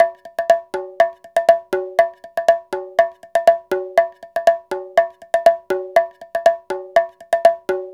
Bongo 01.wav